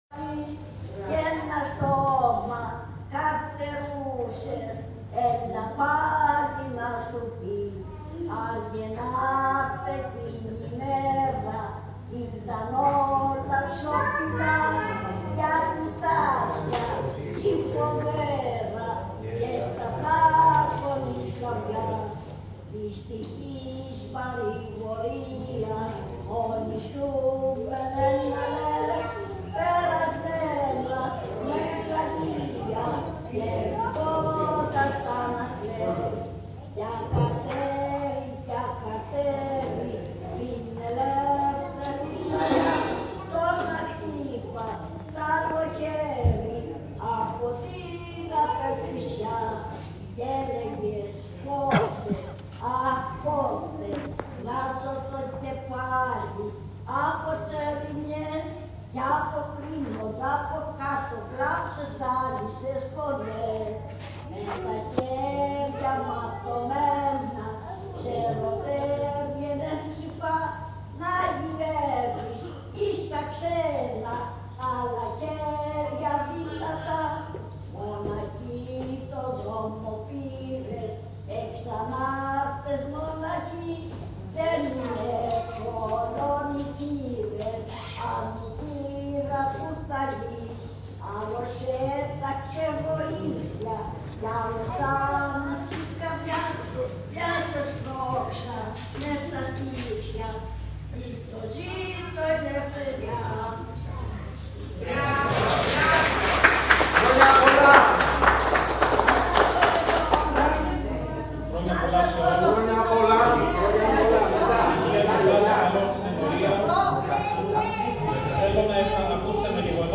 Ακολούθησε ο από άμβωνος
πύρινος λόγος